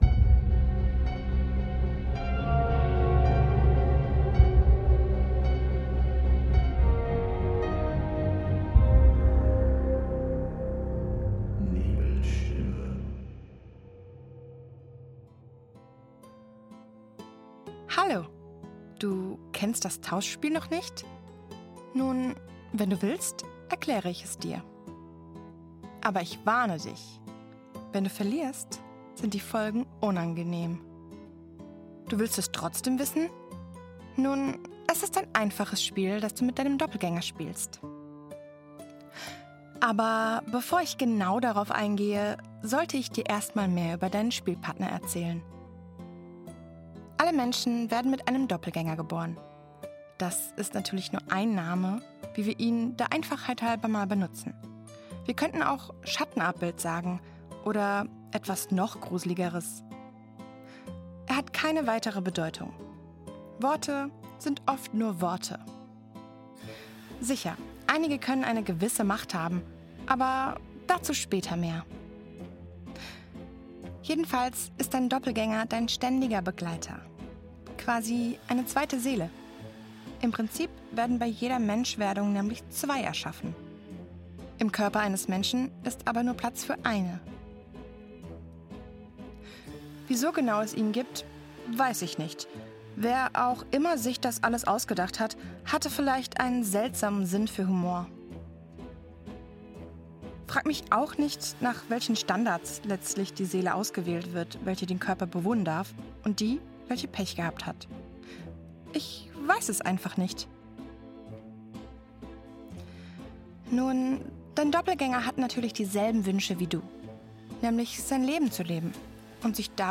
Du hörst hier die überarbeitete Version meiner früheren Vertonung von YouTube – jetzt mit neuer Atmosphäre, frischem Sound und ein bisschen mehr Erzähler-Routine.